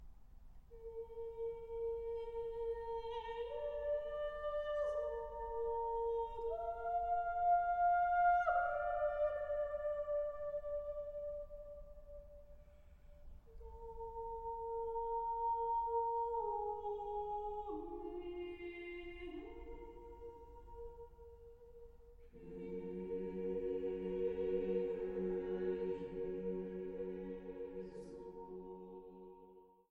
Orgel
eine Nelson-Orgel von 1904